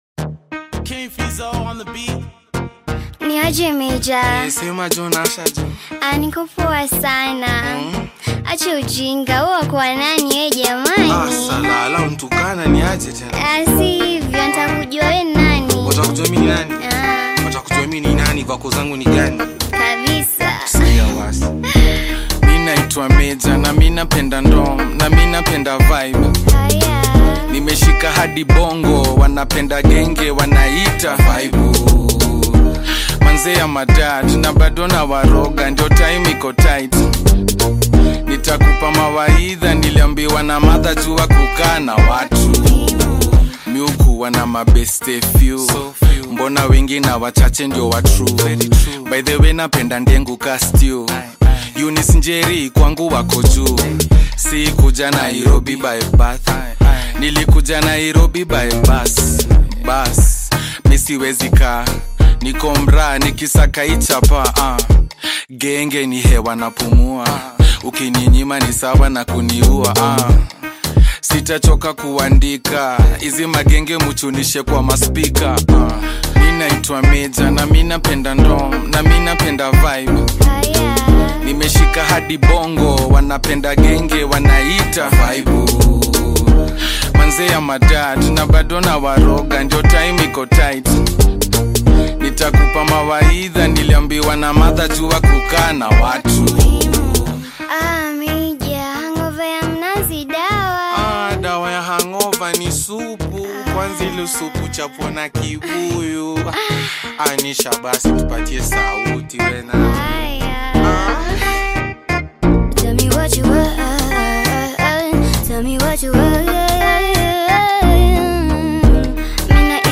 Kenyan music track
Kenyan Genge